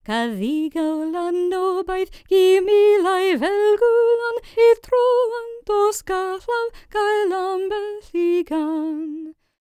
L CELTIC A32.wav